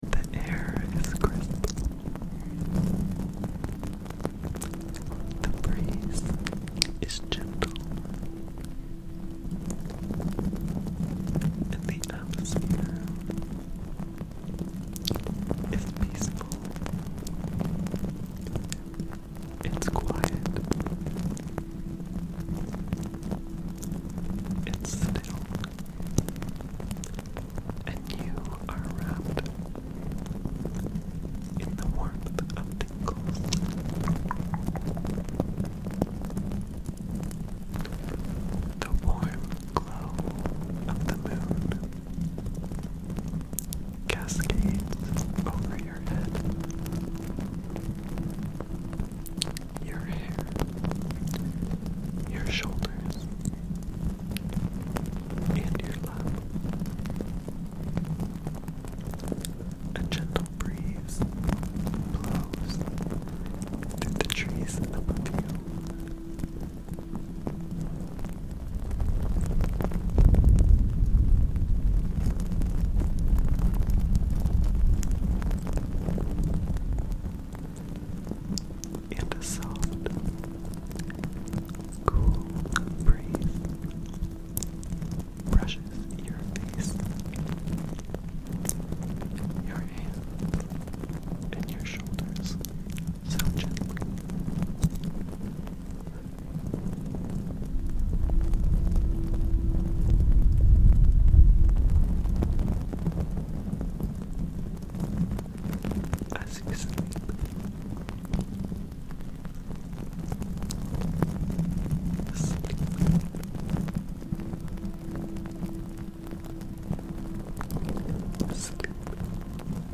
ASMR | Fire Crackle Mic sound effects free download
ASMR | Fire Crackle Mic + Whispers That Melt Your Brain Fire crackle mic sounds, inaudible whispers, and clicky male whispering—all in 3 dreamy minutes to help you unwind and fall asleep fast. This cozy ASMR sleep loop features soft tongue clicks, trigger words like “sleep,” and relaxing loofah-on-mic sounds that mimic a real crackling fire.